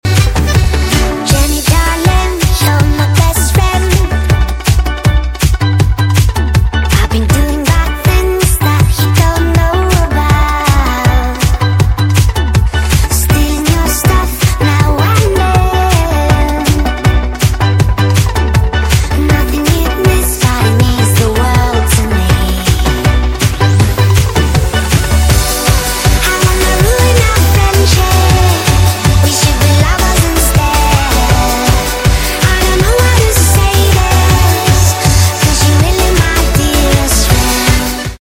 (Sped up)